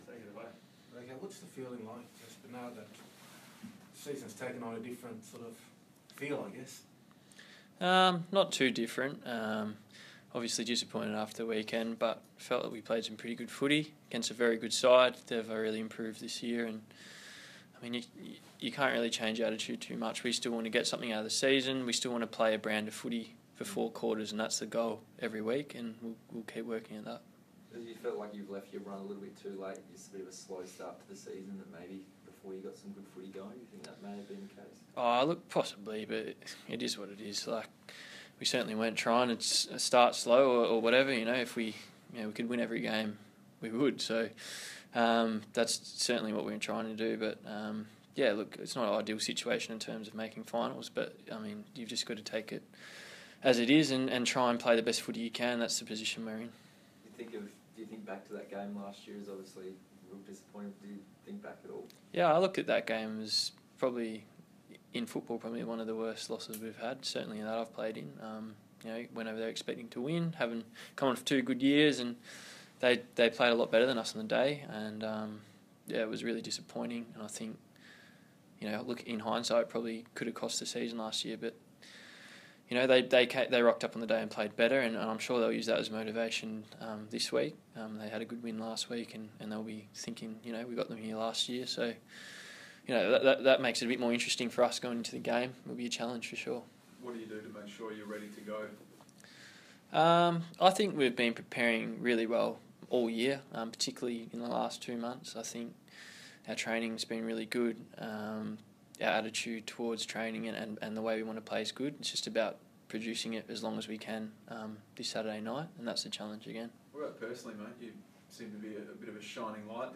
Jasper Pittard Press Conference - Wednesday, 26 July, 2016